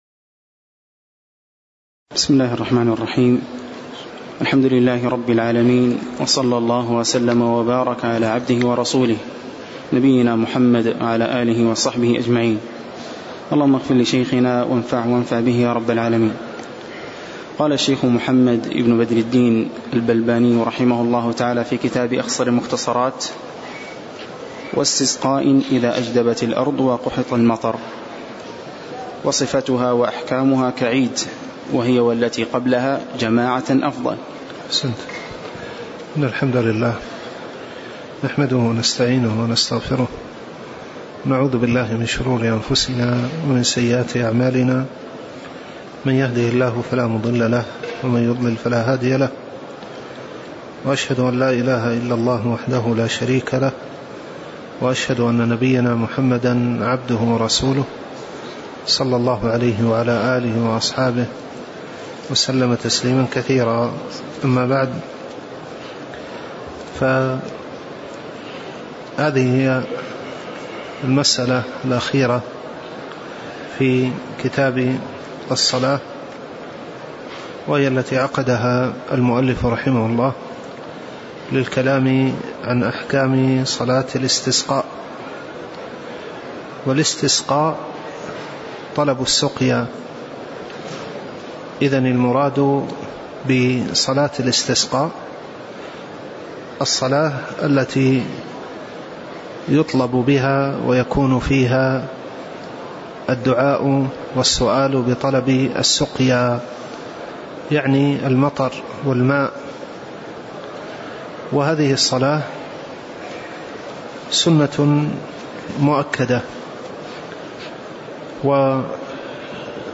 تاريخ النشر ٢٩ رجب ١٤٣٩ هـ المكان: المسجد النبوي الشيخ